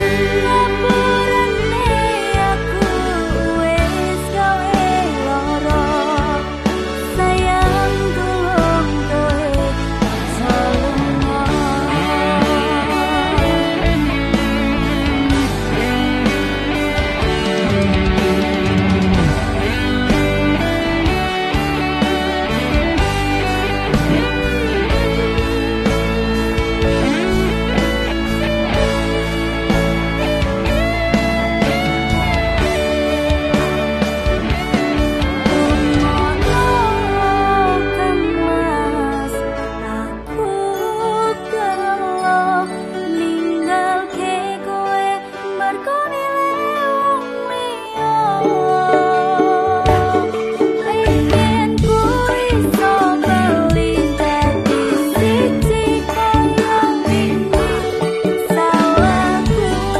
Ukulele